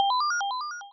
computer_e.wav